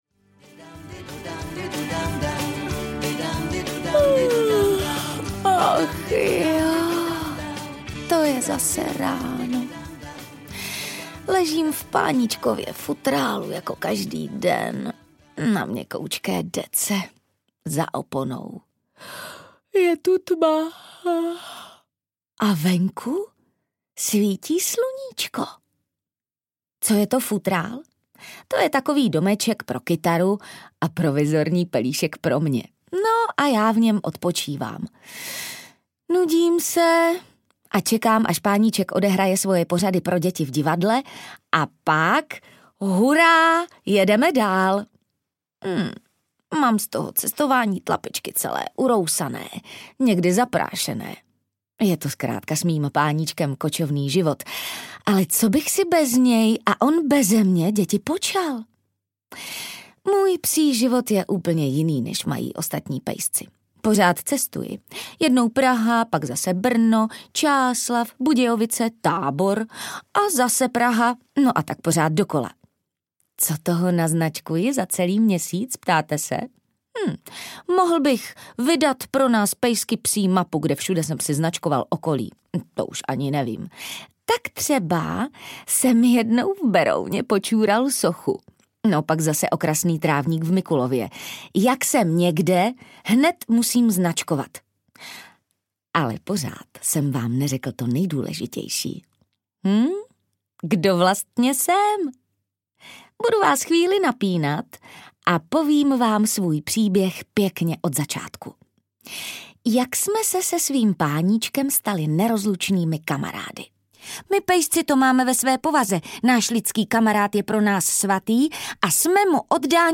Kategorie: Dětské